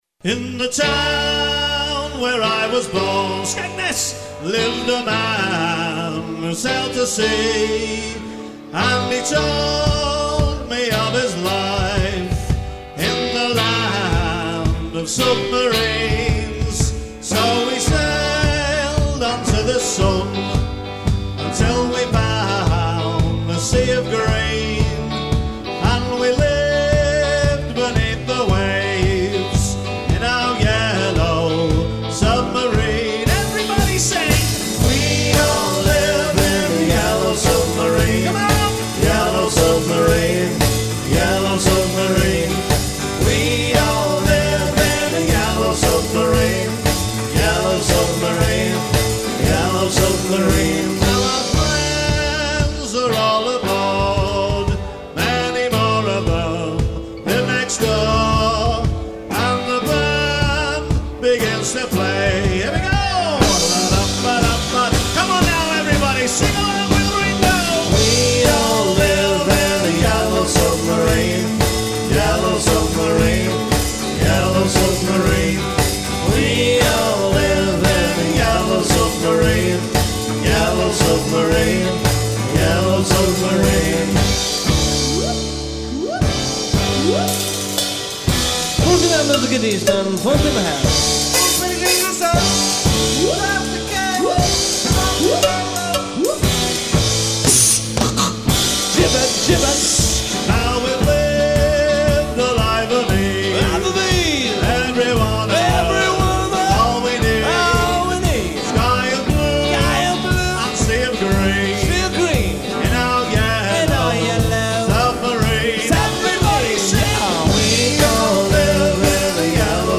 Live recording-